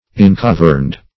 Search Result for " incaverned" : The Collaborative International Dictionary of English v.0.48: Incaverned \In*cav"erned\ ([i^]n*k[a^]v"[~e]rnd), a. Inclosed or shut up as in a cavern.